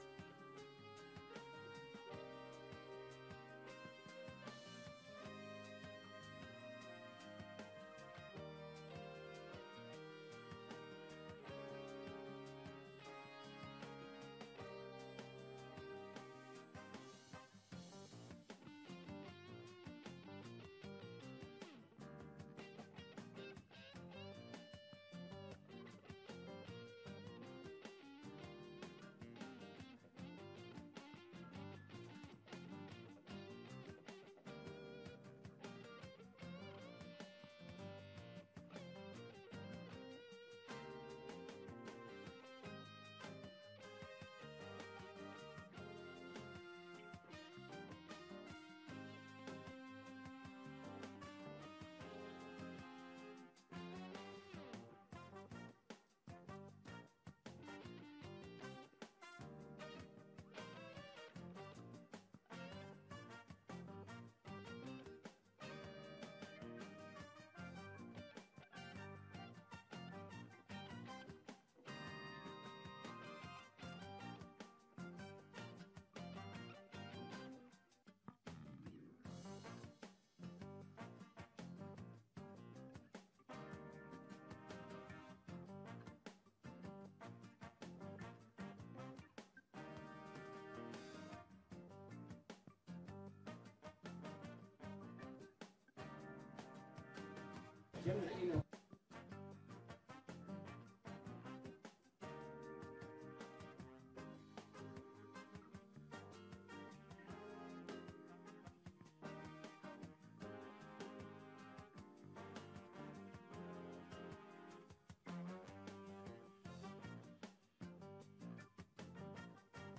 Locatie: Raadzaal
Opening politiek jaar - te gast is Mart de Kruif (luitenant-generaal buiten dienst)